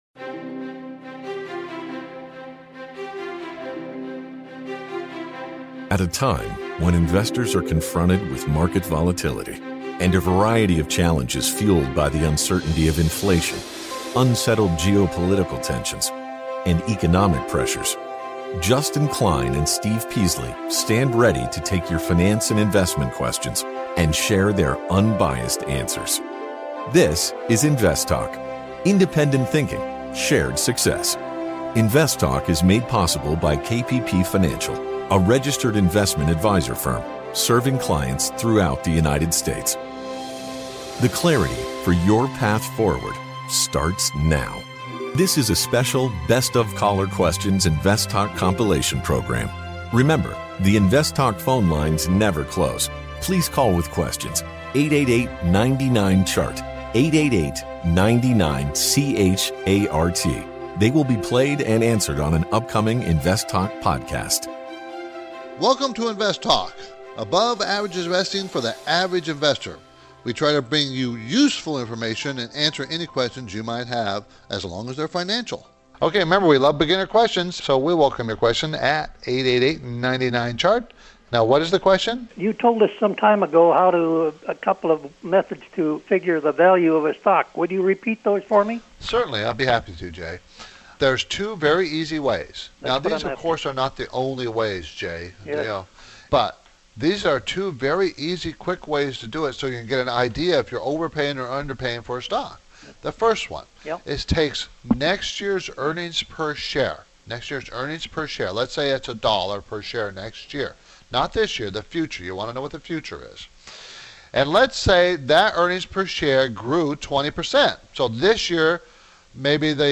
field a variety of finance and investment questions from callers across the United States and around the world